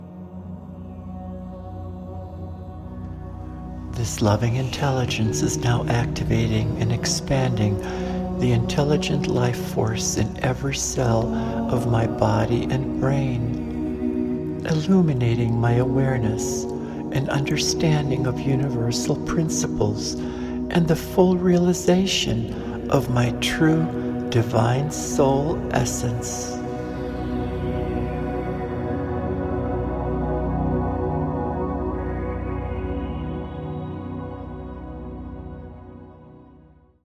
AN ADVANCED GUIDED MEDITATION